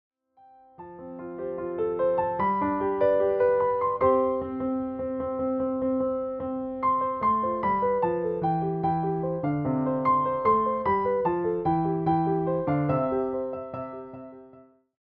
each one carrying a warm, traditional Christmas feeling.